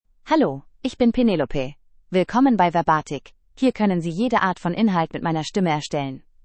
FemaleGerman (Germany)
PenelopeFemale German AI voice
Voice sample
Listen to Penelope's female German voice.
Female